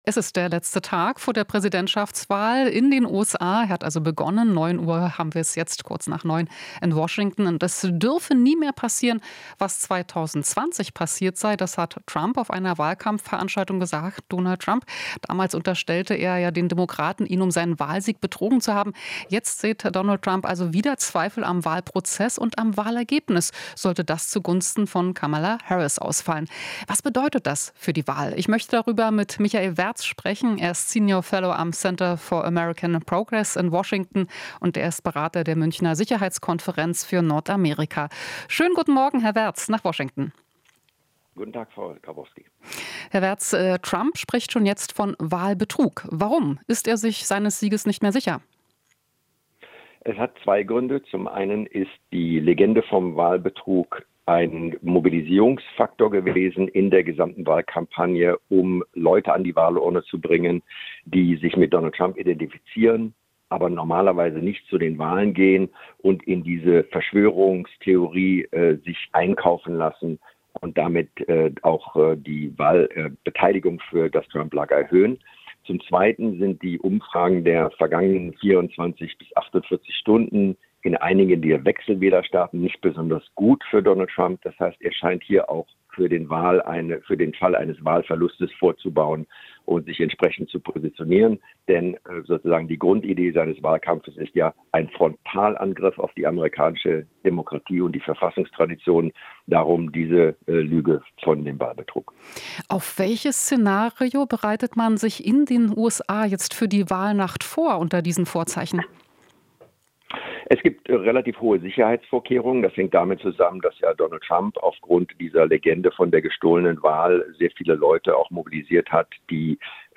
Interview - US-Experte: Trump plant "Frontalangriff auf die Demokratie"